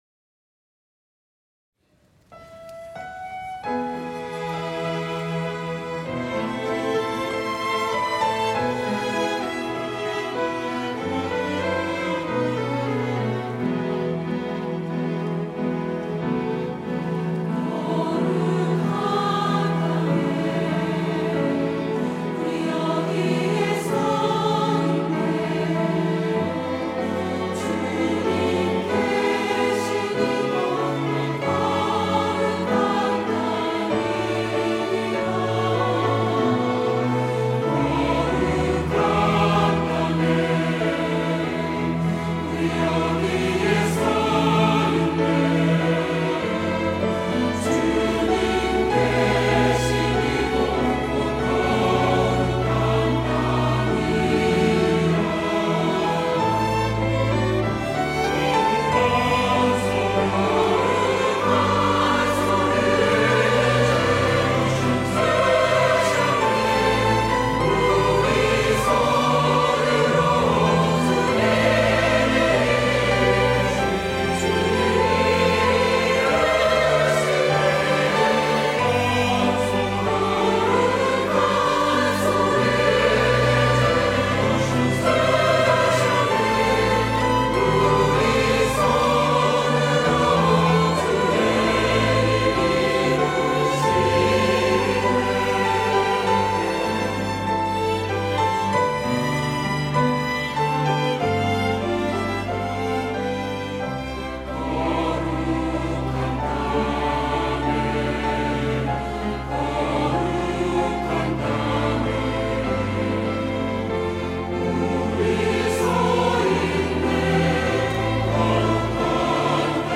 호산나(주일3부) - 거룩한 땅에
찬양대